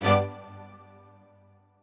admin-leaf-alice-in-misanthrope/strings34_2_004.ogg at main